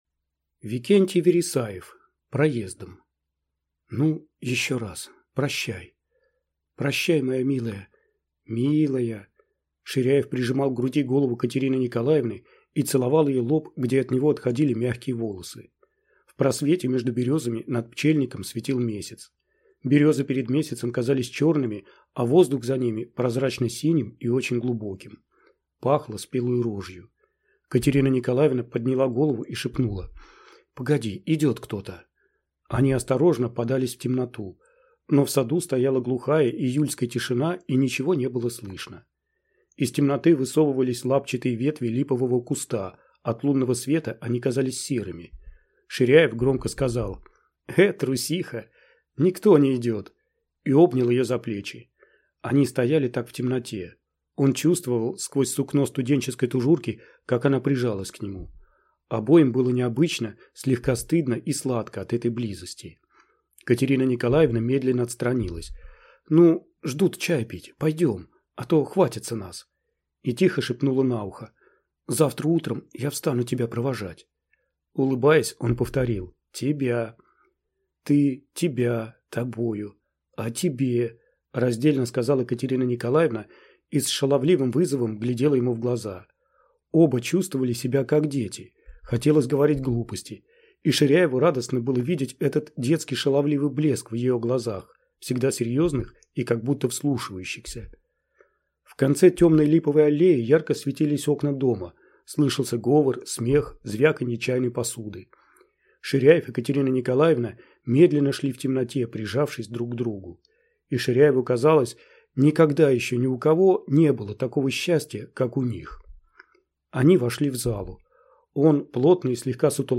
Аудиокнига Проездом | Библиотека аудиокниг